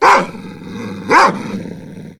bdog_attack_3.ogg